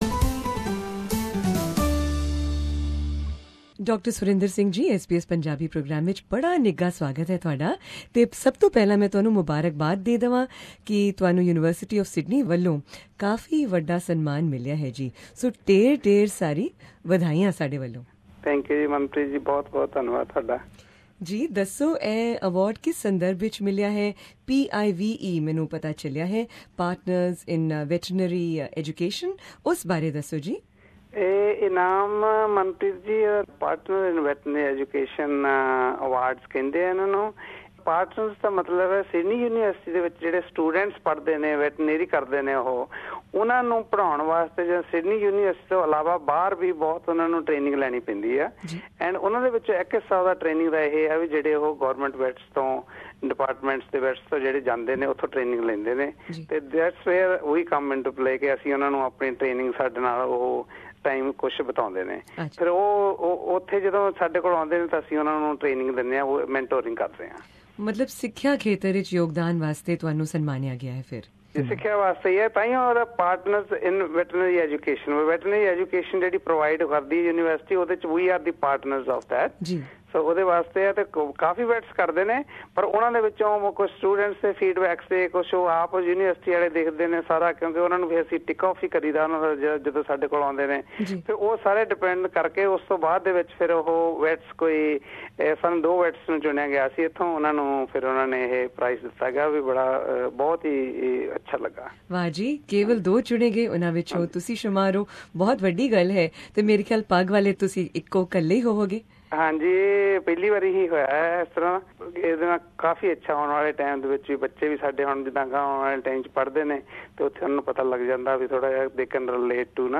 Here is a brief interview